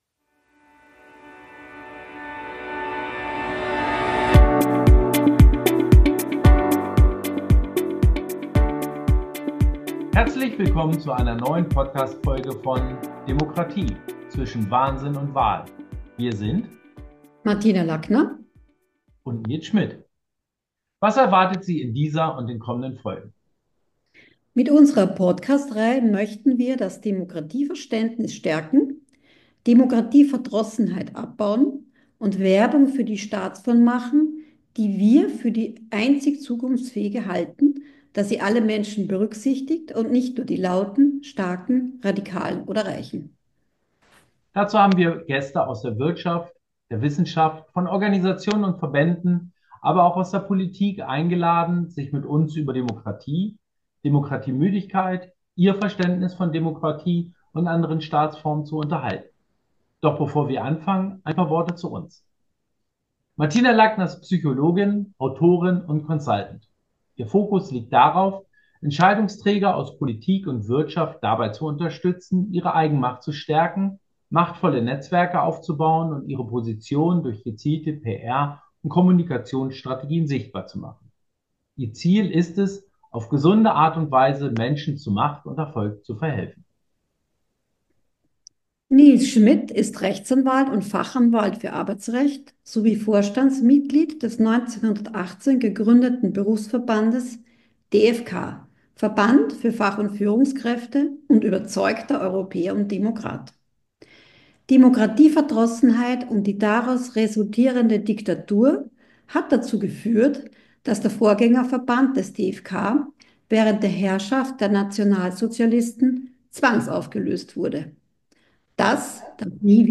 In dieser Folge sprechen wir mit Maria Rauch-Kallat.
Diese und viele weitere Fragen klären wir im Gespräch.